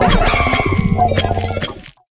Cri de Vert-de-Fer dans Pokémon HOME.